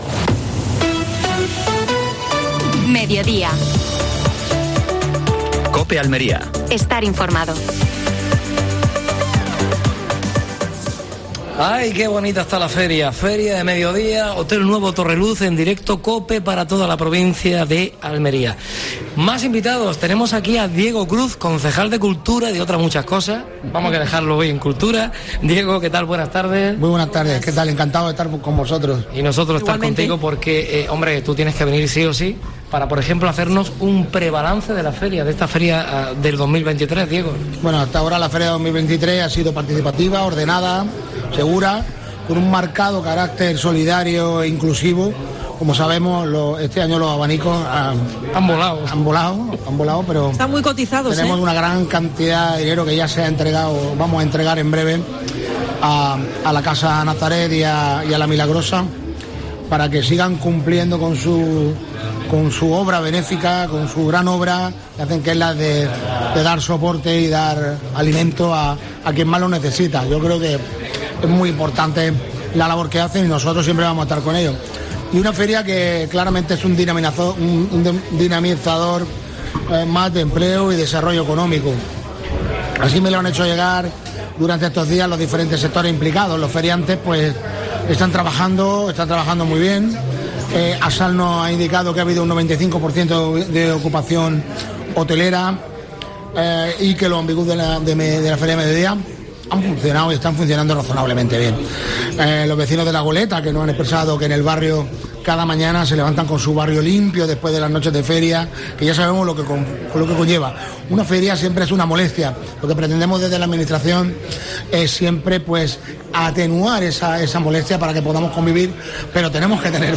AUDIO: Programa especial de la Feria de Almería. Desde el Hotel Torreluz. Entrevista a Diego Cruz (concejal de Cultura del Ayuntamiento de Almería).